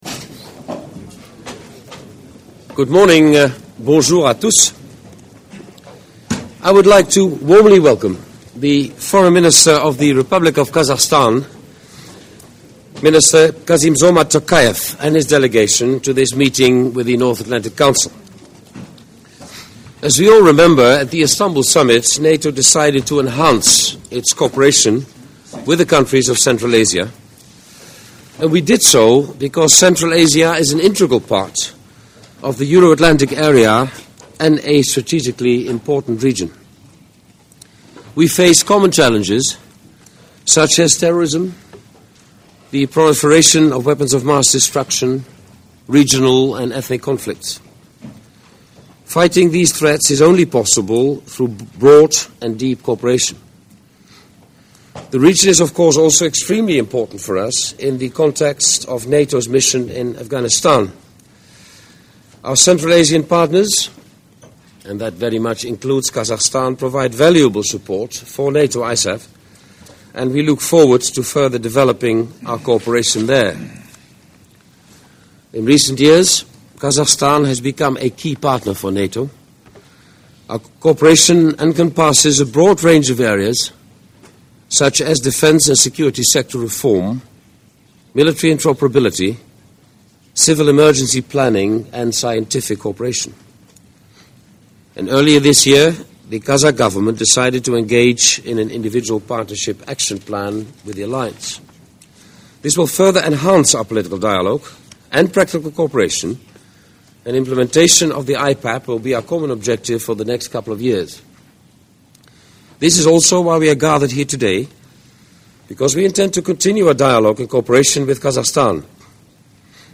Audio Opening remarks by NATO Secretary General, Jaap de Hoop Scheffer and Mr. Kassymzhomart Tokayev, Foreign Minister of Kazakhstan at the meeting of the North Atlantic Council, opens new window